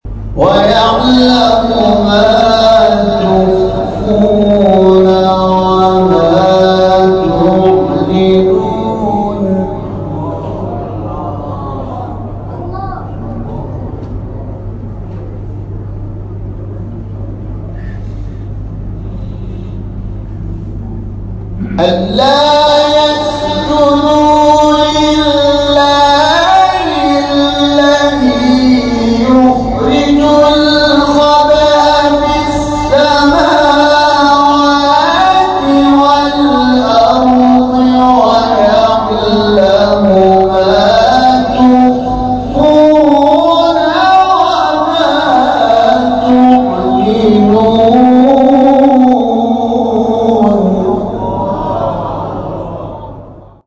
در ادامه تلاوت ها و گزارش تصویری این کرسی ها ارائه می‌شود.